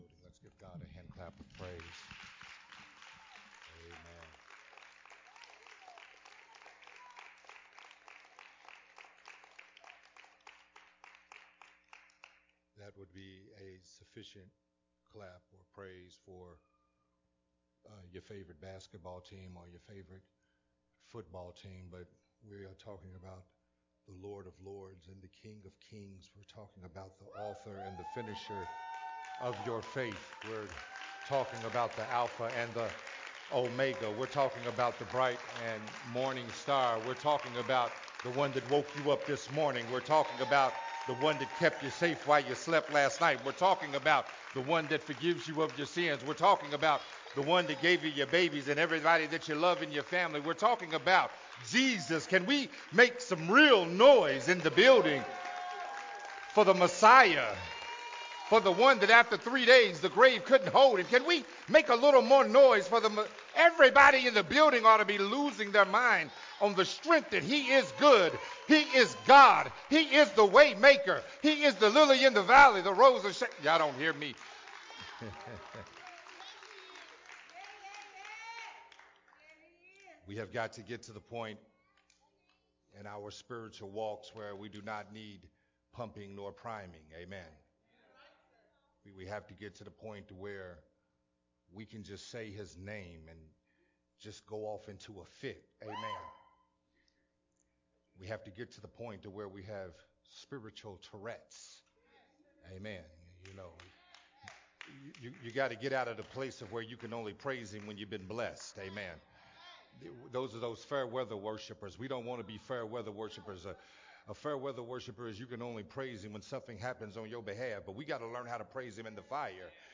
Part 4 of the sermon series “A Love That Restores”.
Recorded at Unity Worship Center on August 22, 2021.